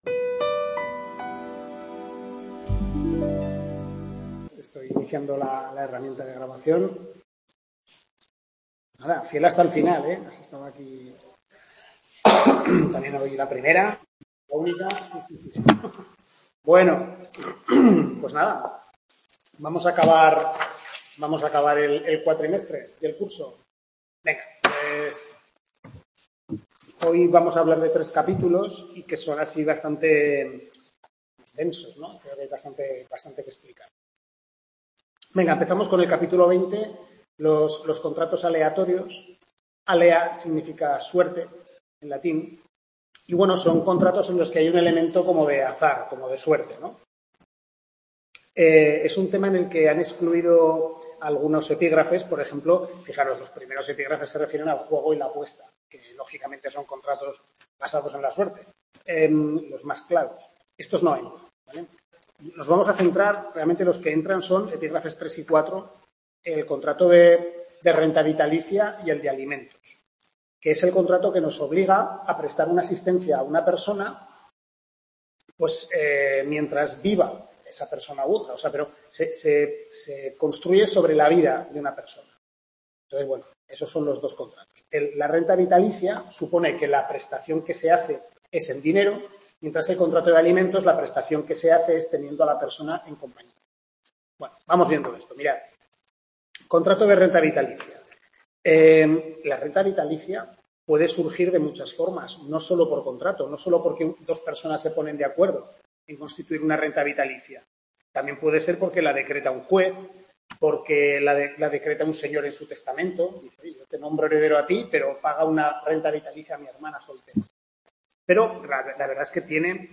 Tutoría 6/6, segundo cuatrimestre Derecho civil II (Contratos), centro UNED-Calatayud, capítulos 20-23 del Manual del Profesor Lasarte